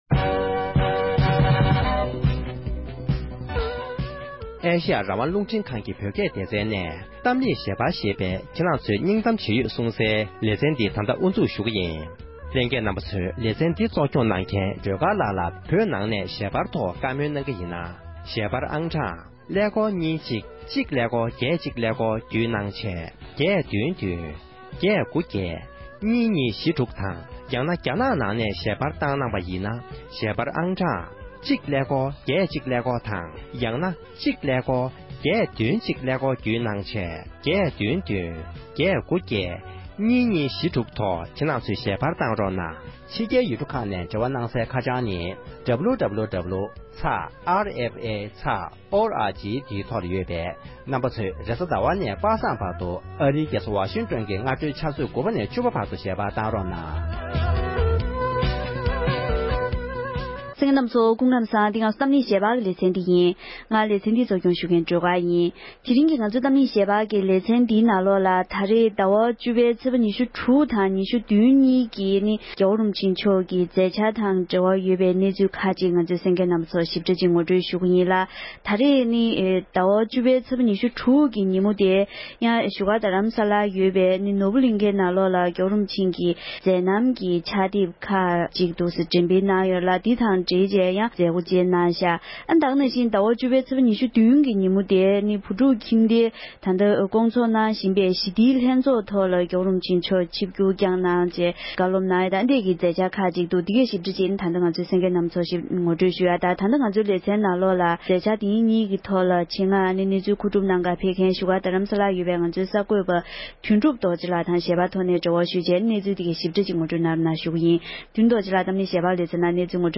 འབྲེལ་ཡོད་མི་སྣའི་ལྷན་གླེང་མོལ་གནང་བར་གསན་རོགས༎